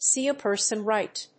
アクセントsée a person ríght